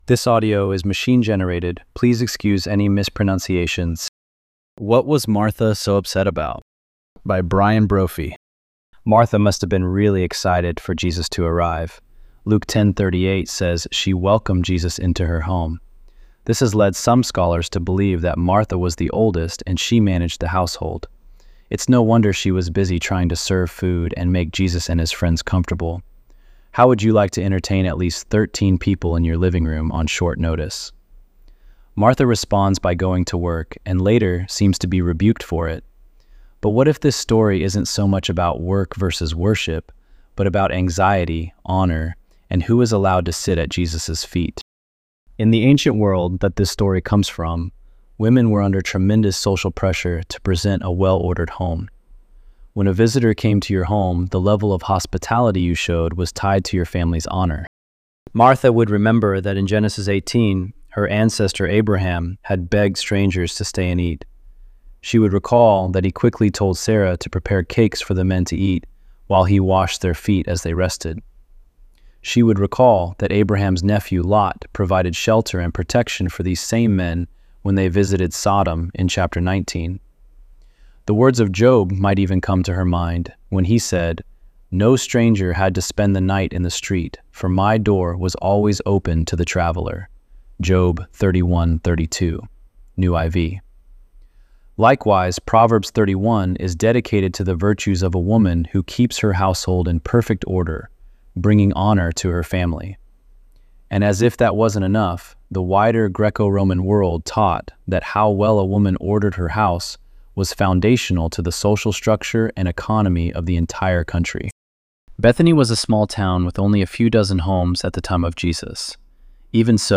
ElevenLabs_3_11.mp3